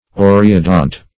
oreodont - definition of oreodont - synonyms, pronunciation, spelling from Free Dictionary Search Result for " oreodont" : The Collaborative International Dictionary of English v.0.48: Oreodont \O"re*o*dont\, a. (Paleon.)